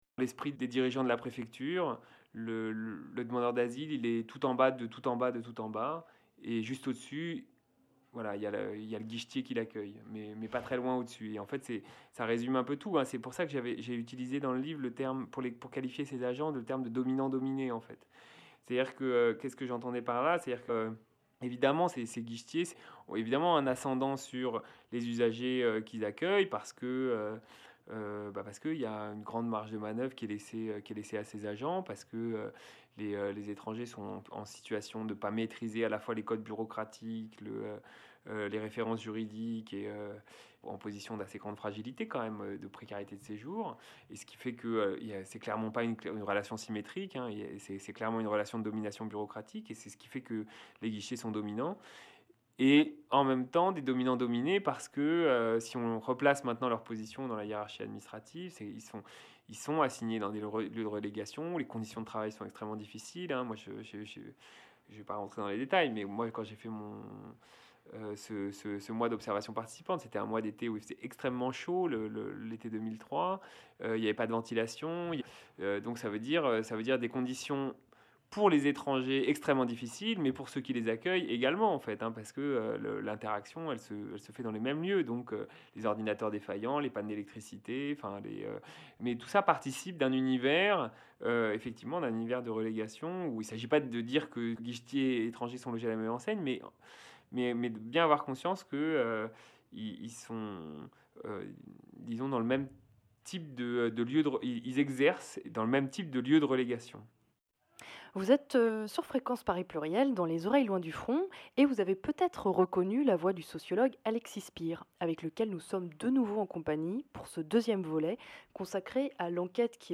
Deuxième et dernière partie d’un entretien